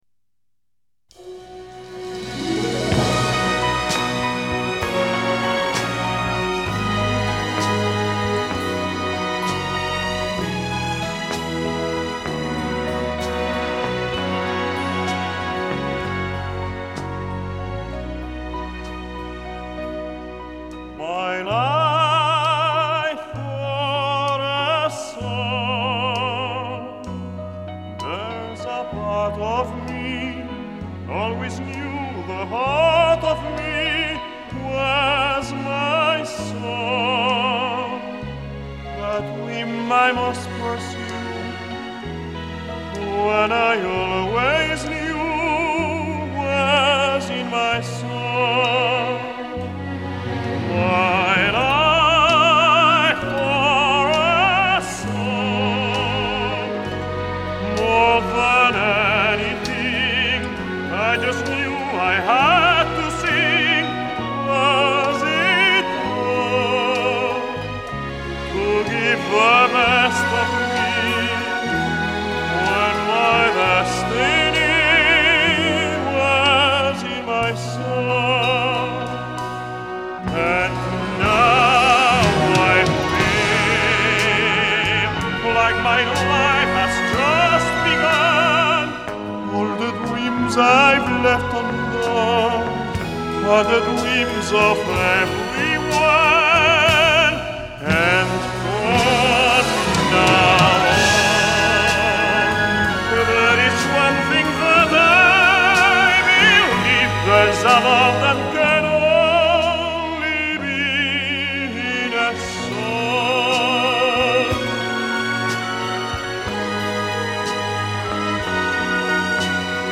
类型：Vocal
风格：Opera